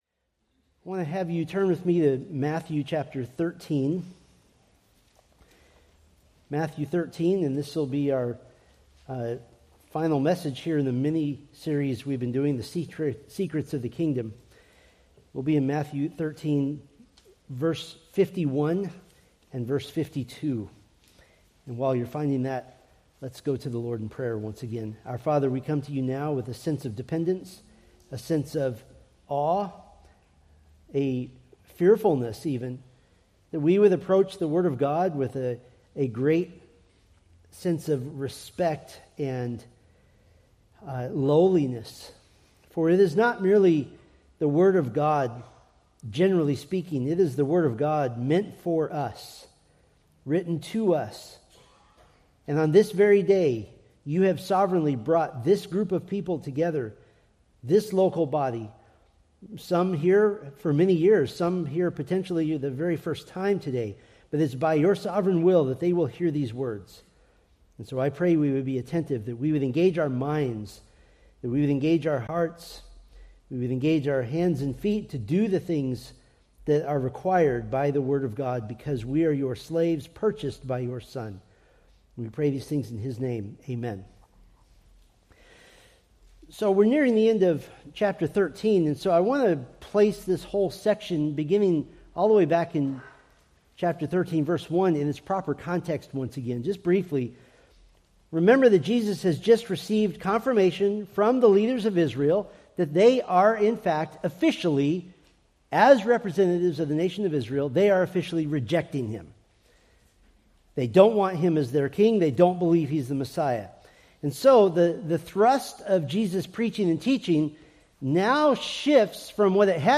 Preached April 12, 2026 from Matthew 13:51-52